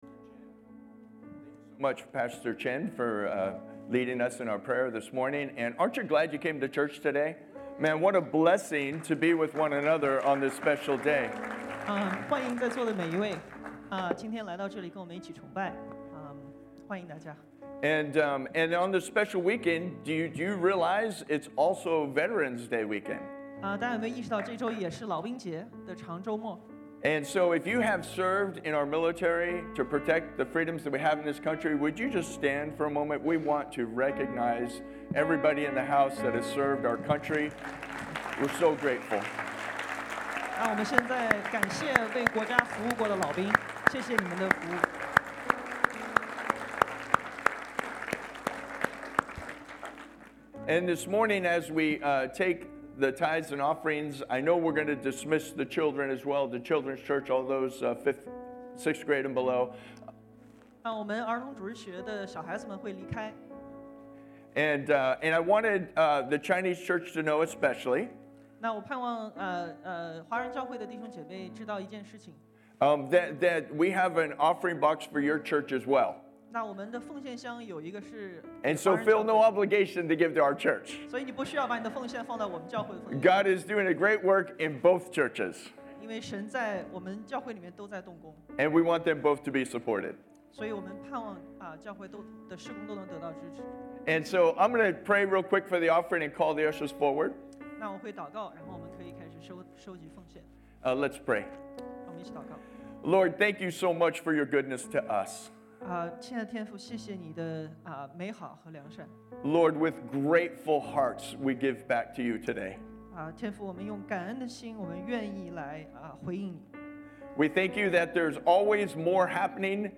Service Type: Sunday AM